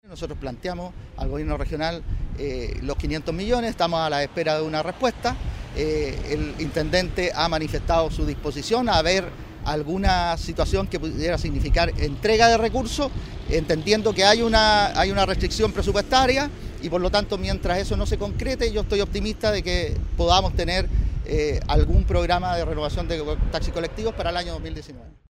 La restricción presupuestaria sería la causa de la falta de recursos para llevar adelante el programa, según dijo el seremi de Transportes y Telecomunicaciones, Jaime Aravena, quien se mostró optimista e informó que solicitaron 500 millones de pesos para ejecutar la iniciativa.